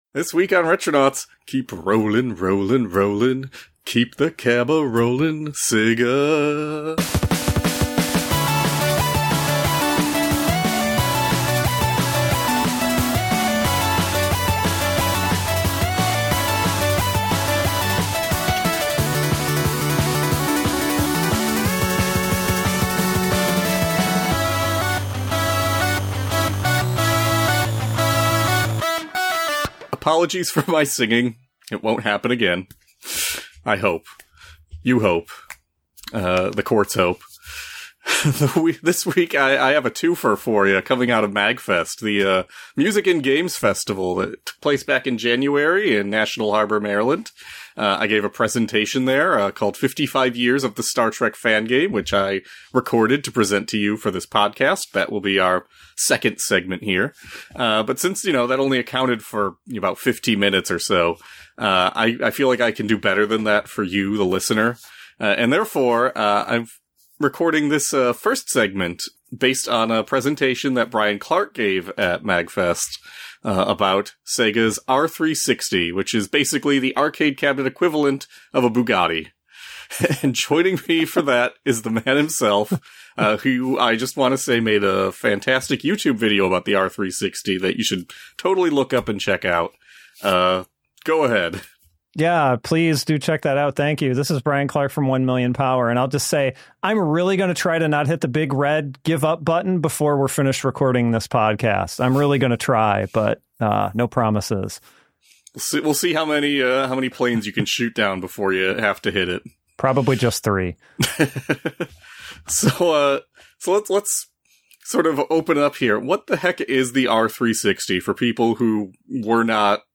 Two months into 2026 and our first live recording of the year has arrived on the podcast. We had a modest Retronauts presence at MAGFest back in January which we can now offer to your ears for entertainment purposes.